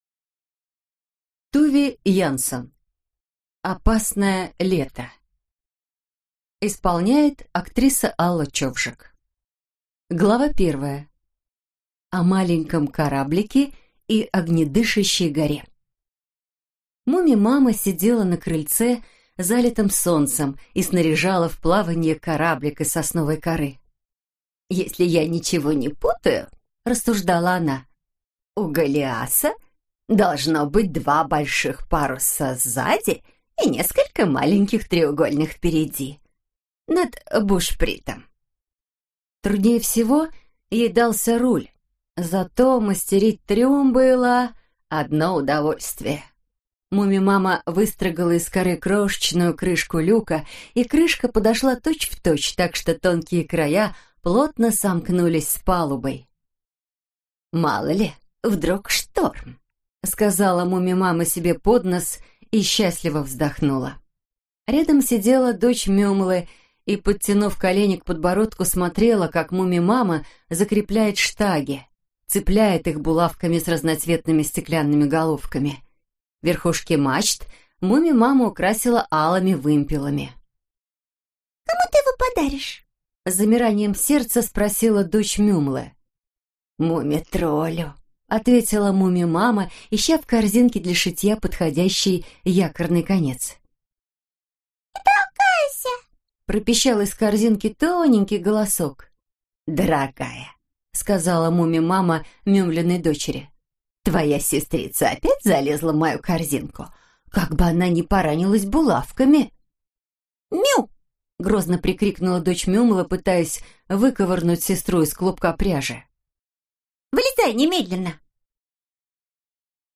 Аудиокнига Опасное лето - купить, скачать и слушать онлайн | КнигоПоиск